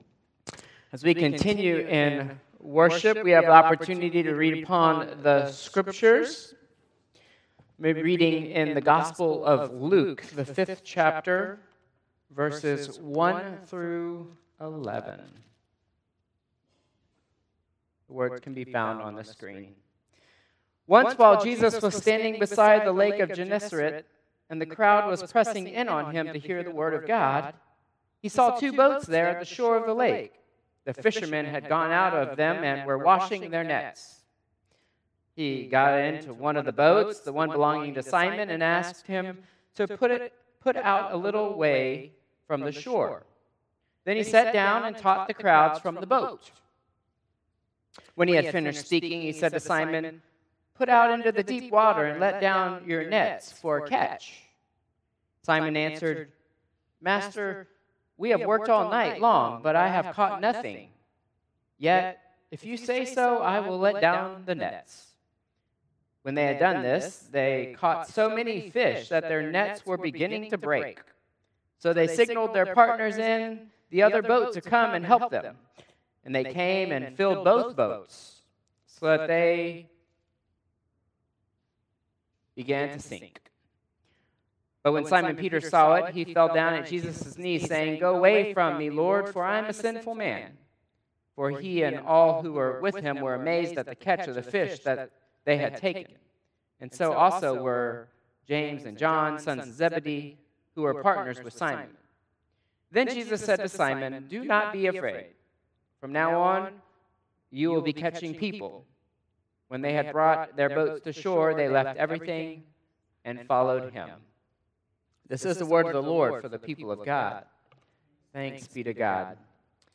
Traditional Service 8/10/2025